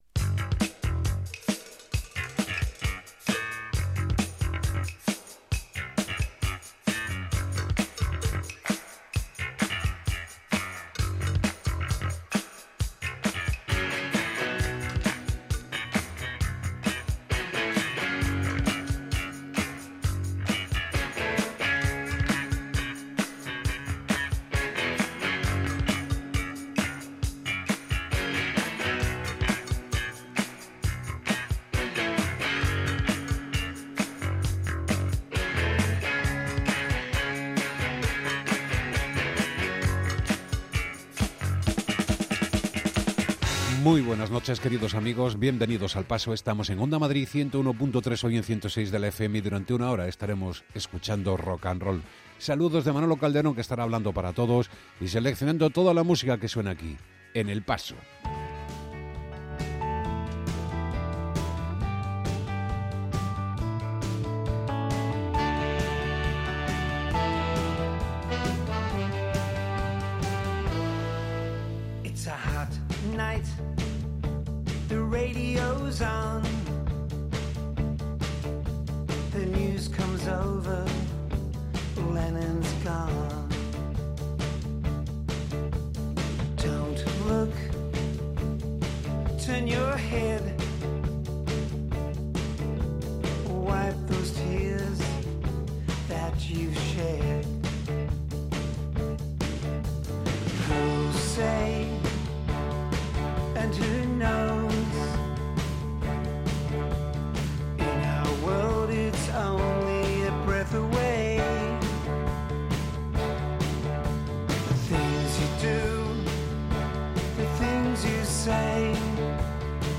No hay década mala en el repaso musical que hace El Paso, desde el primigenio rock de los años 50 hasta el blues contemporáneo, pasando por el beat, power pop, surf, punk, rock o música negra.